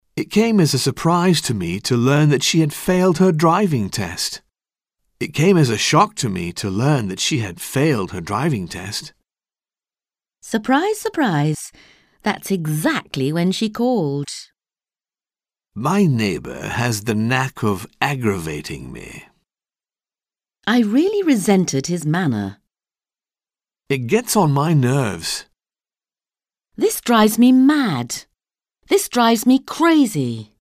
Un peu de conversation - Surprise et colère